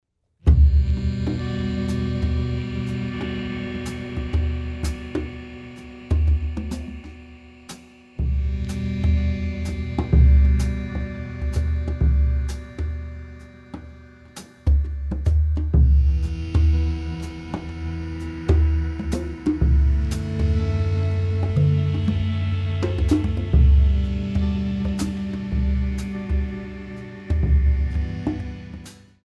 multi hand drum set
accordion, synthesizer